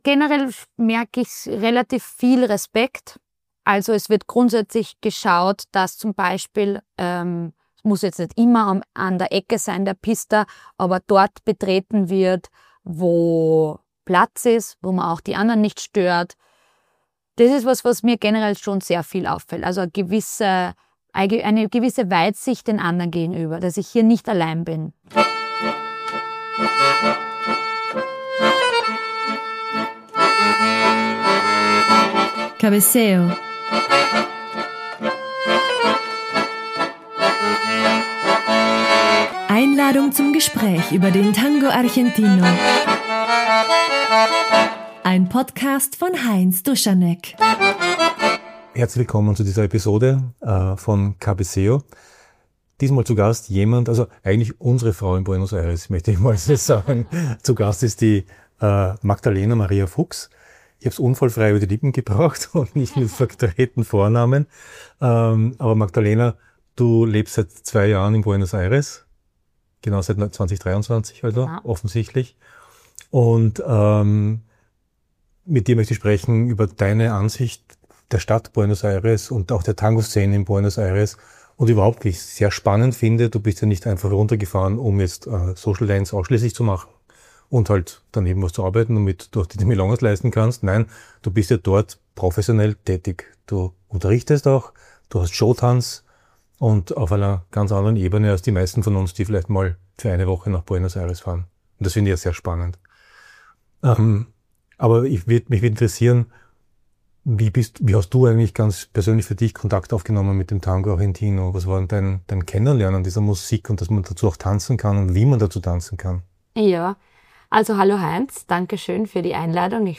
Ich habe die Gelegenheit genutzt und sie zu einem Gespräch eingeladen.